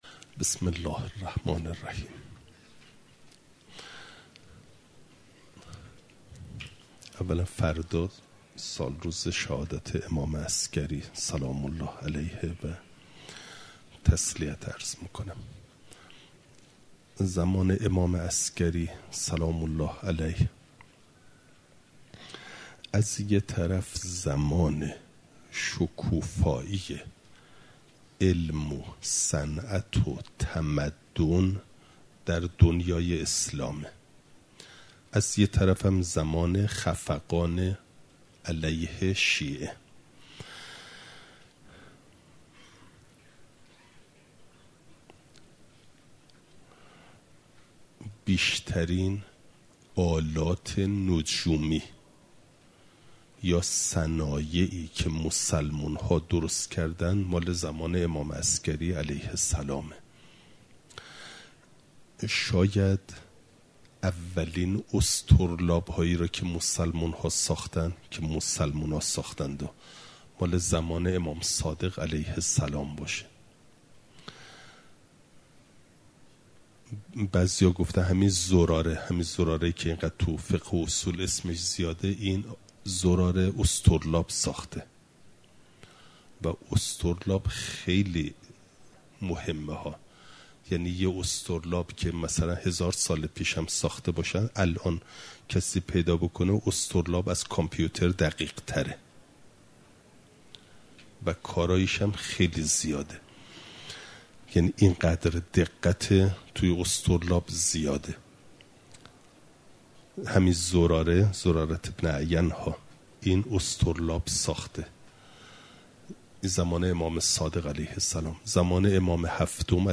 خارج اصول، مقدمه واجب (جلسه ۱۸) – دروس استاد